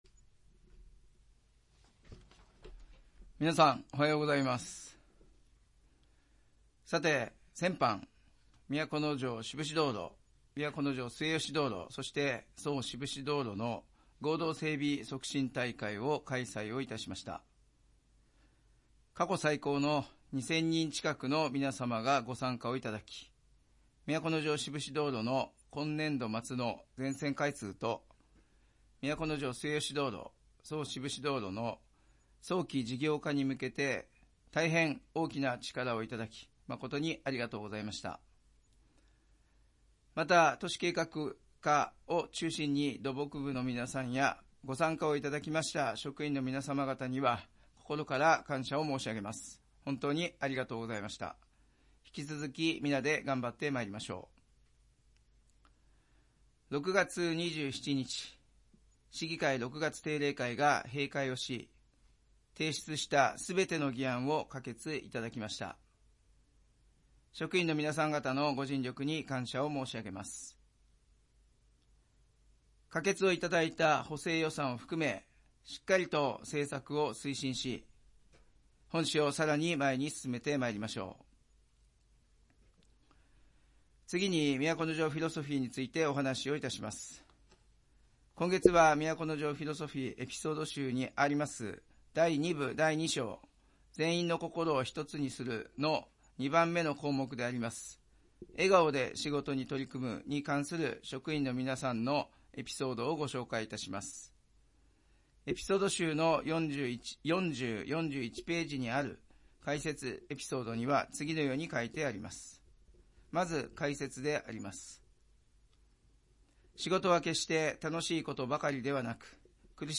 市長が毎月初めに行う職員向けの庁内メッセージを掲載します。